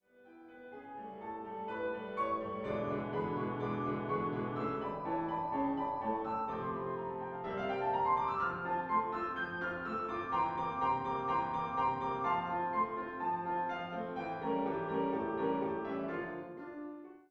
Klavier-Sound